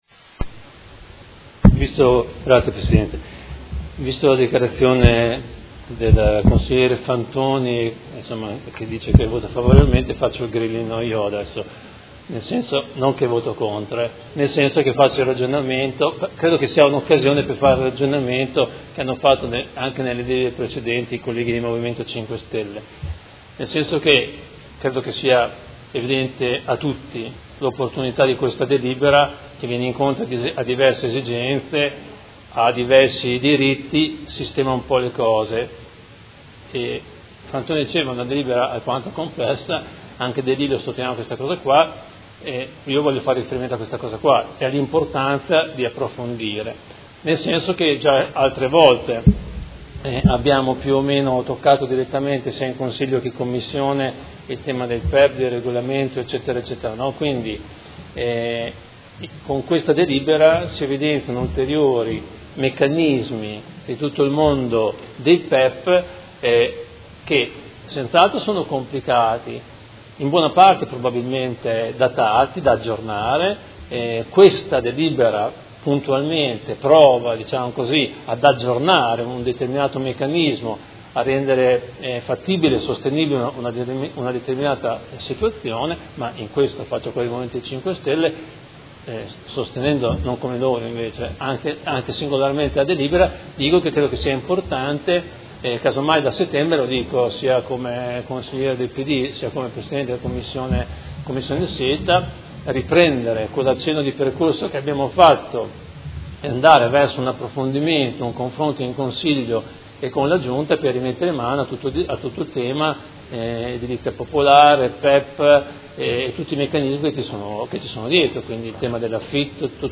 Seduta del 20/07/2017 Dibattito. Alloggi con vincolo di locazione a termine nel P.E.E.P. “Panni”, lotto 7 – Scadenza del vincolo di destinazione alla locazione - Apposizione di nuovo vincolo decennale in sostituzione o trasferimento dell’obbligo di locazione a termine da un ugual numero di alloggi nel Comparto “Ghiaroni” - Accoglimento e condizioni